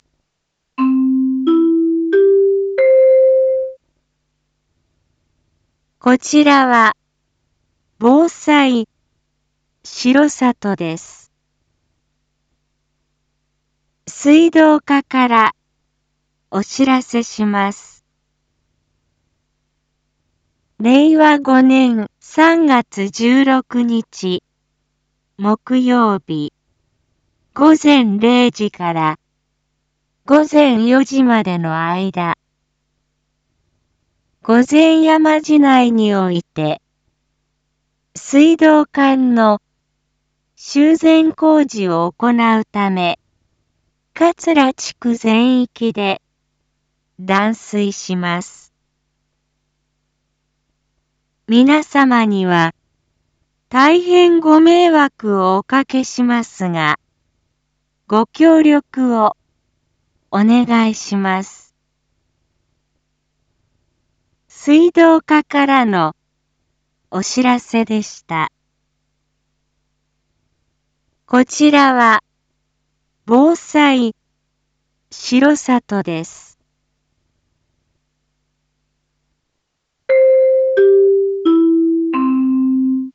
Back Home 一般放送情報 音声放送 再生 一般放送情報 登録日時：2023-03-11 19:06:24 タイトル：水道断水のお知らせ（桂地区限定） インフォメーション：こちらは、防災しろさとです。